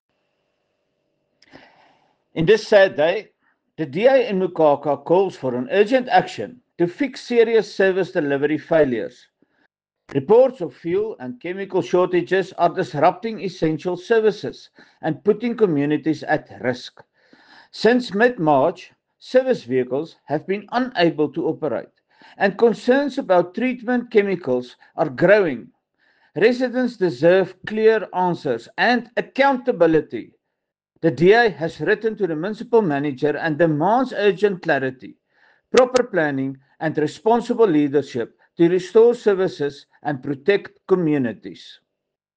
Afrikaans soundbites by Cllr Johan Spaski Geldenhuis.